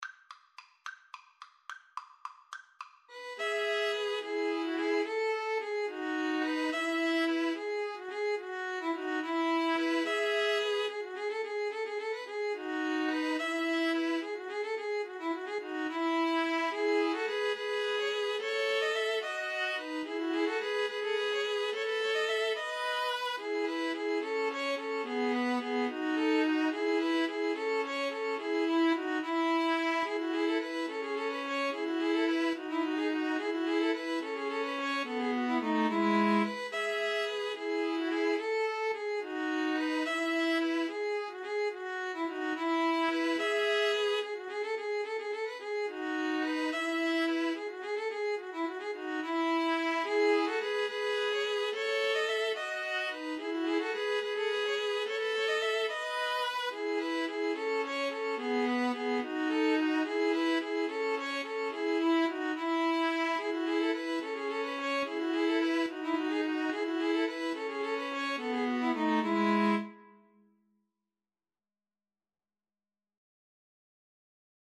Free Sheet music for String trio
E major (Sounding Pitch) (View more E major Music for String trio )
6/4 (View more 6/4 Music)
Traditional (View more Traditional String trio Music)